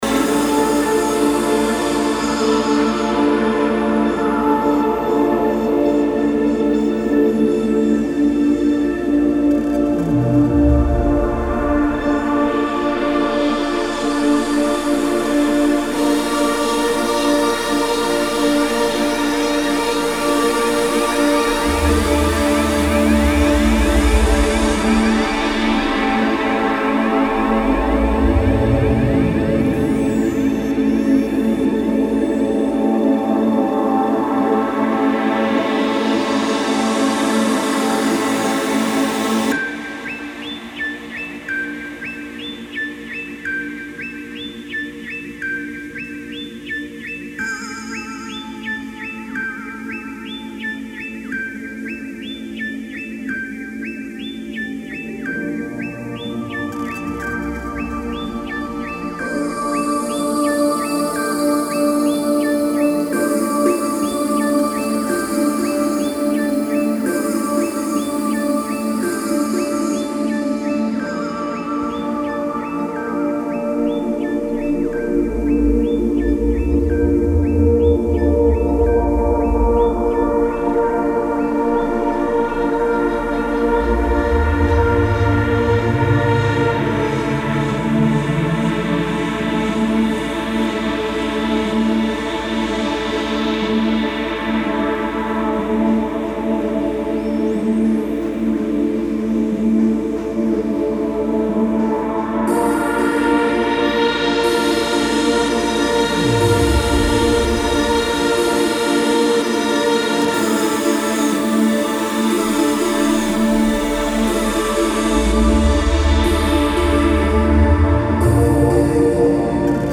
B面には美麗なシンセ・テクスチャーに焦点が当てられたノンビートver.を収録。